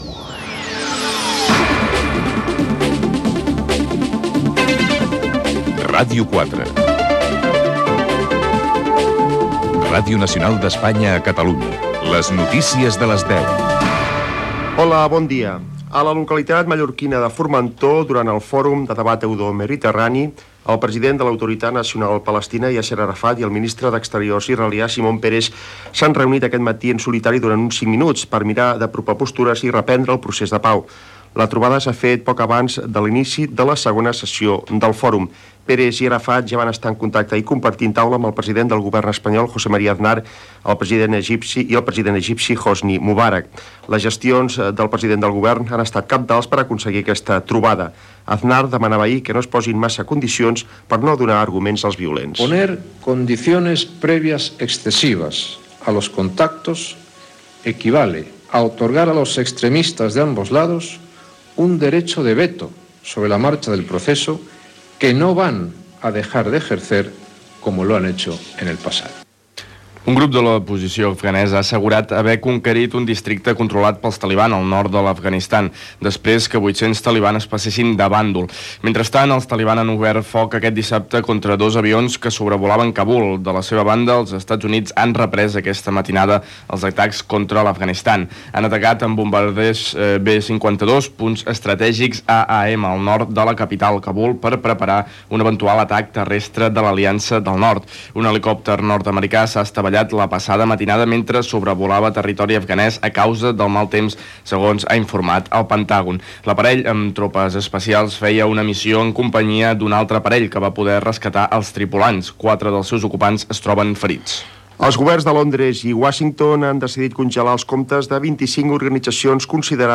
Careta del programa, informació de la reunió entre Simón Péres i Yasser Arafat, guerra d'Afganistan, País Basc
Informatiu